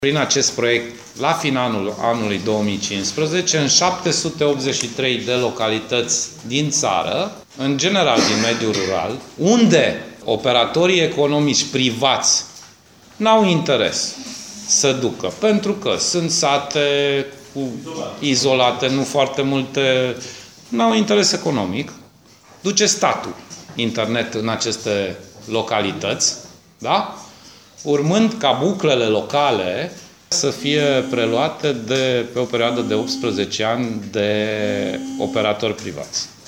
22 de localități din Timiș și aproape 800 la nivel național vor beneficia de internet de mare viteză prin investiții ale statului, din fonduri europene. Anunțul a fost făcut de ministrul pentru Societatea Informațională, Sorin Grindeanu, care a spus că lucrările trebuie finalizate până la sfârșitul acestui an: